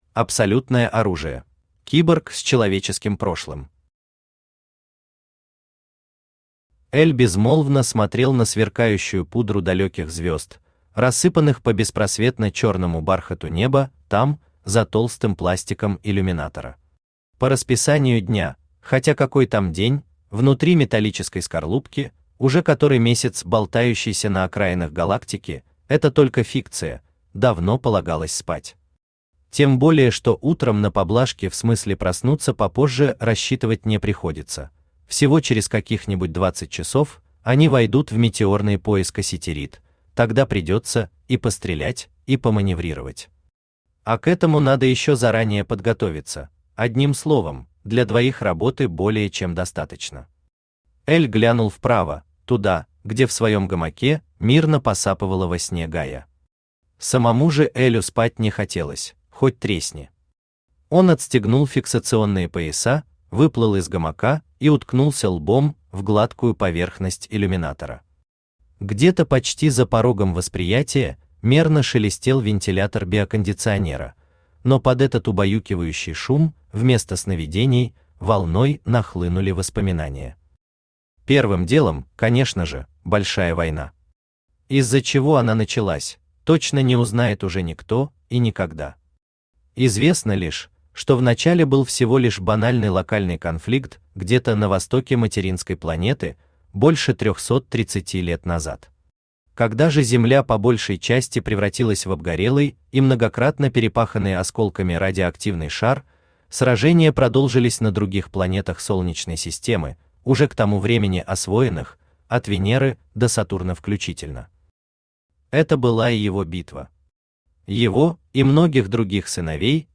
Аудиокнига По эту сторону матрицы | Библиотека аудиокниг
Aудиокнига По эту сторону матрицы Автор Д. Красковский Читает аудиокнигу Авточтец ЛитРес.